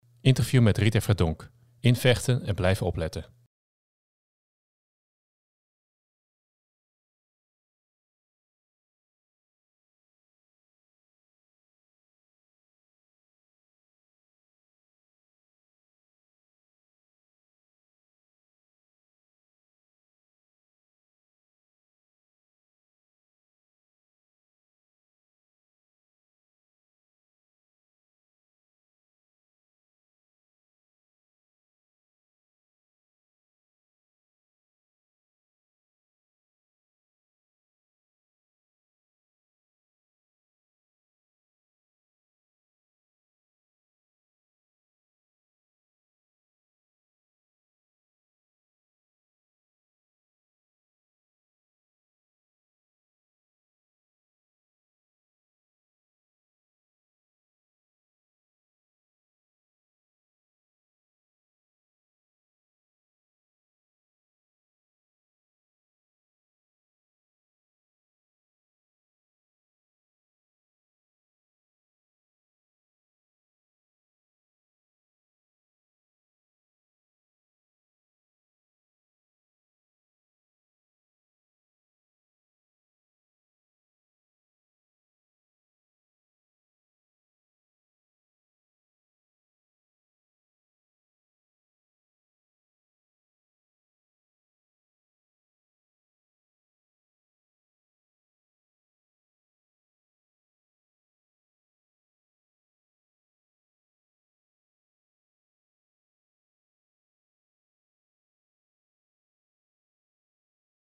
Interview met Rita Verdonk